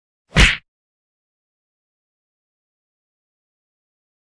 赤手空拳击中肉体6-YS070524.wav
通用动作/01人物/03武术动作类/空拳打斗/赤手空拳击中肉体6-YS070524.wav
• 声道 立體聲 (2ch)